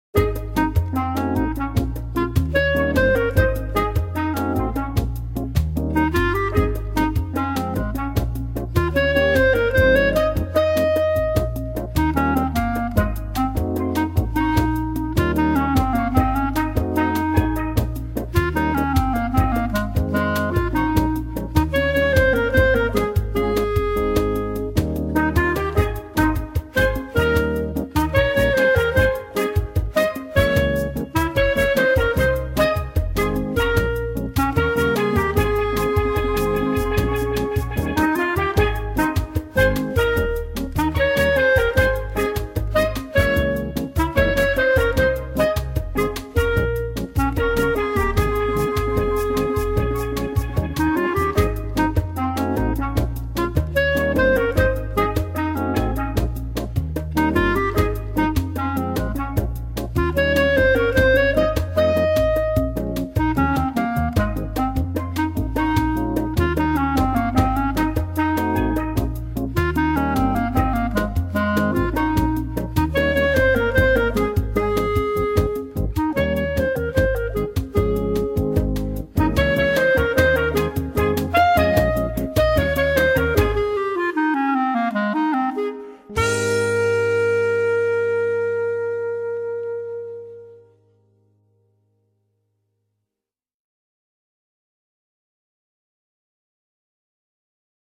Bb Clarinet + CD (MP3s supplied free on request)